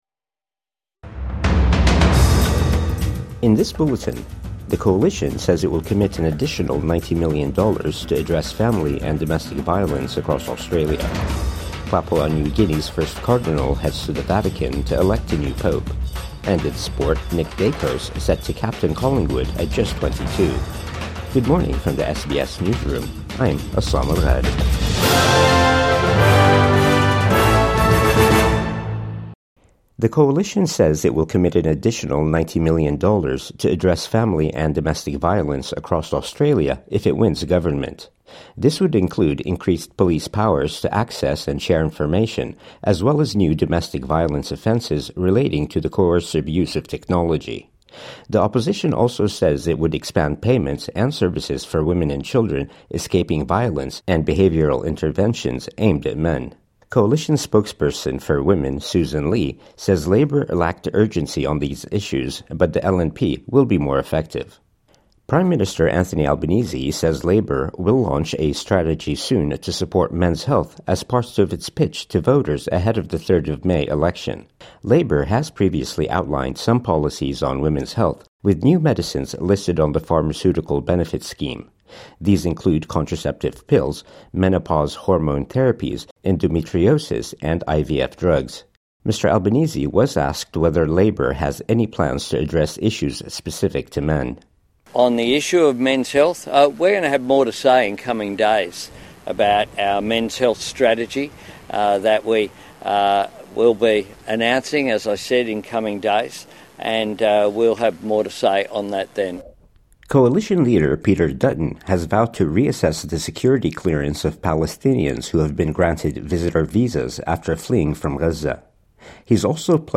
Coalition promises funding to combat domestic violence | Morning News Bulletin 24 April 2025